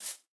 Minecraft Version Minecraft Version 25w18a Latest Release | Latest Snapshot 25w18a / assets / minecraft / sounds / block / bamboo / sapling_hit4.ogg Compare With Compare With Latest Release | Latest Snapshot
sapling_hit4.ogg